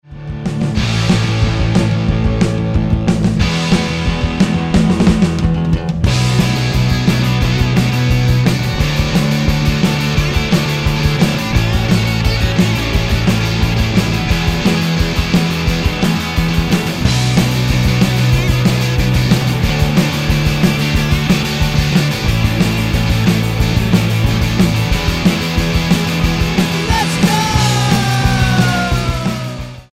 glam punk